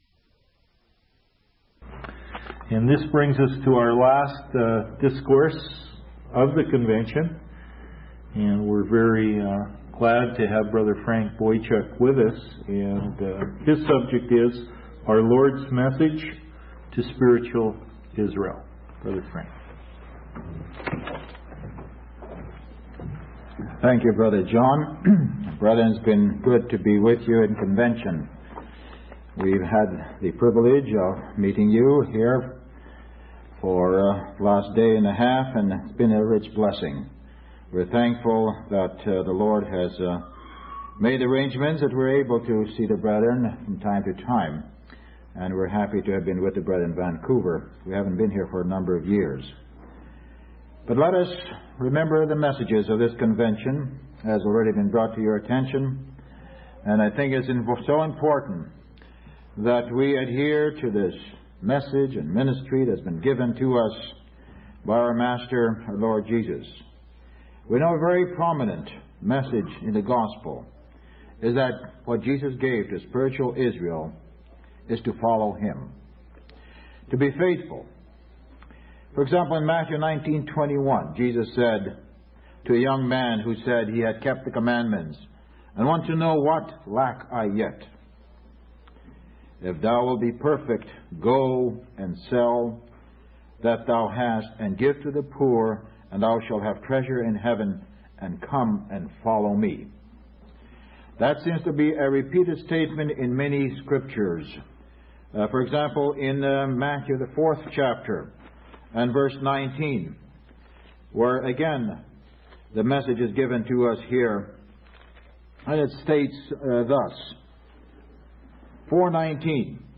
From Type: "Discourse"
Vancouver BC 1991